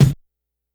Kick_64.wav